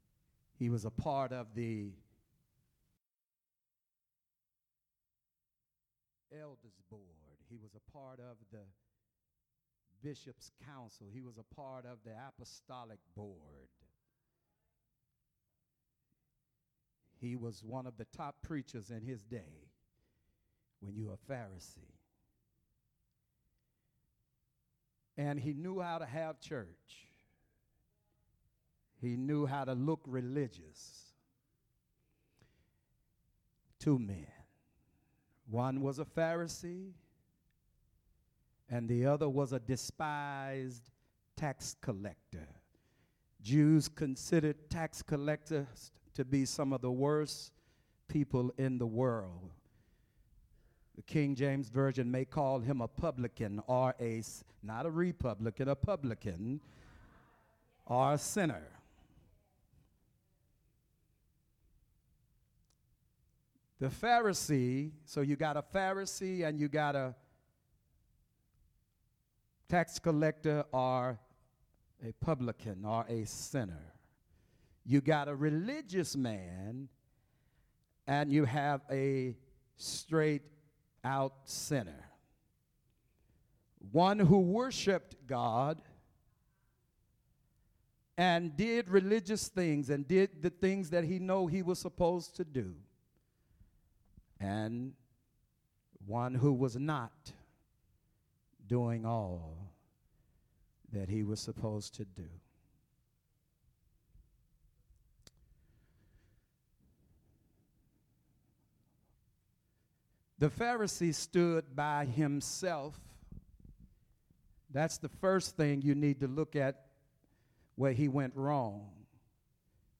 Sermons - Bountiful Blessings World Fellowship